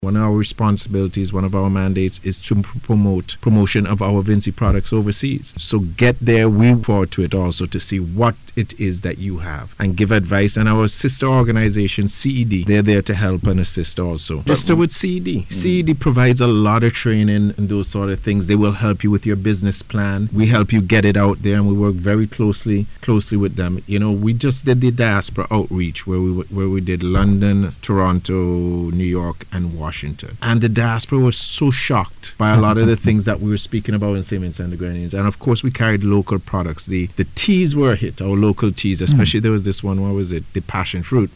speaking on NBC radio recently.